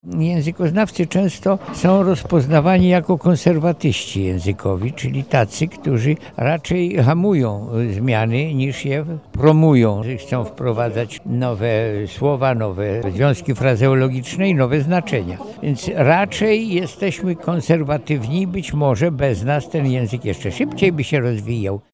Pierwszym prelegentem kongresu był językoznawca, profesor Jerzy Bralczyk. Jak zaznacza, eksperci w tym zakresie raczej nie są zwolennikami szybkich zmian językowych.